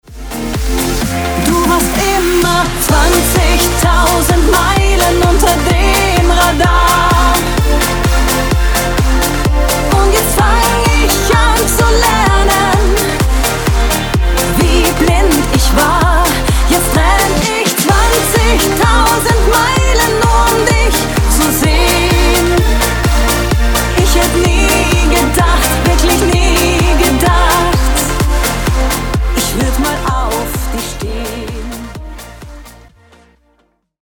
Genre: Schlager